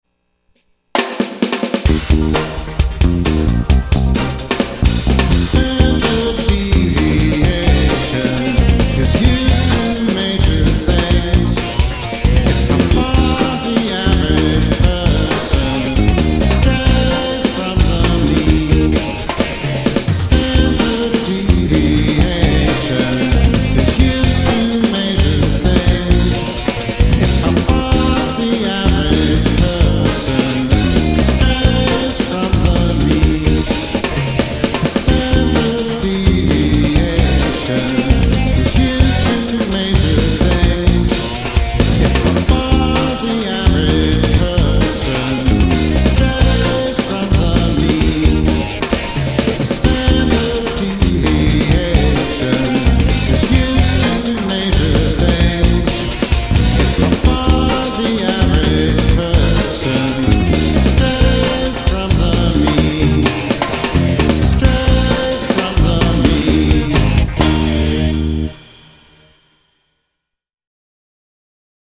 This is a page of stat music.